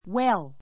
wél ウェ る